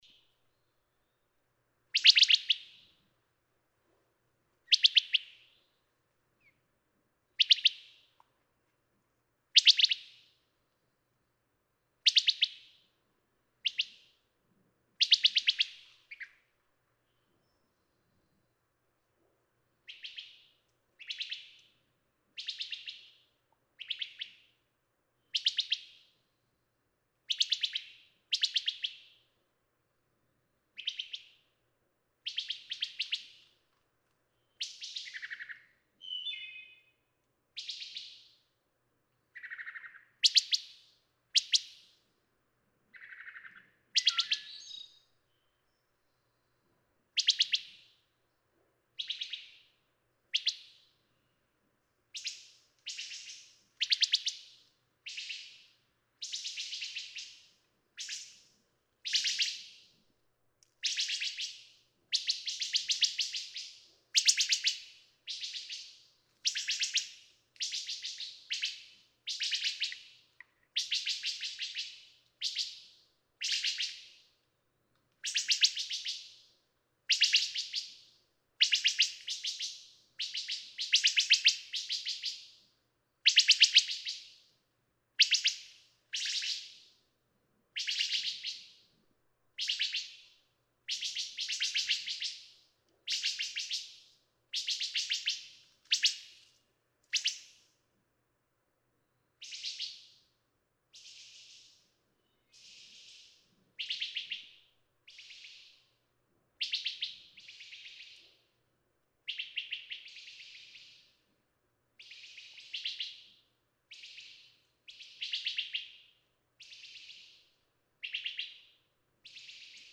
Wood thrush
♫458. Call notes of two wood thrushes going to roost, with soft tuts, sharper whits, and an occasional incomplete song as well, the interaction between these two birds surging and fading until all is quiet in the forest.
Tye River Gap, Blue Ridge Parkway, Virginia.
458_Wood_Thrush.mp3